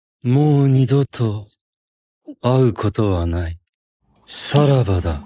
本格的なネイティブ日本語ナレーション
テキスト読み上げ
ネイティブイントネーション